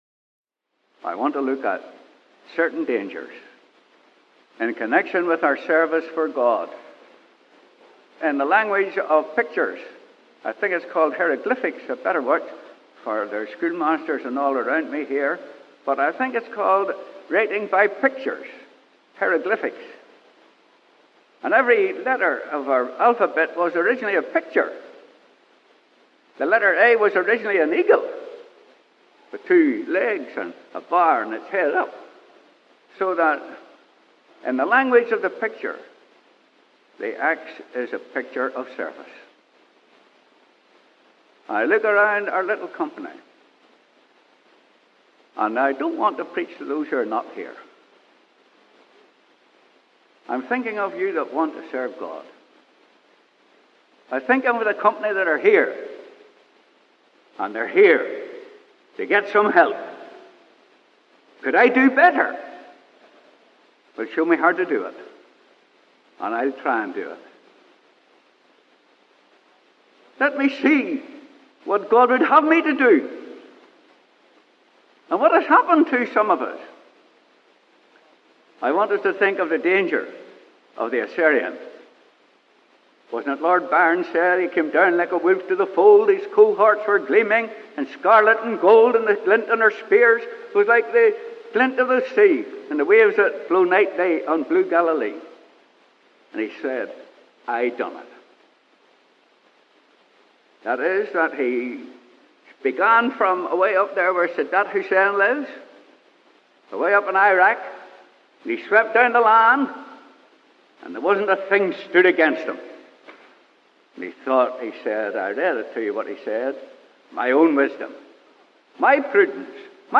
(Recorded at the Belfast Easter Conference)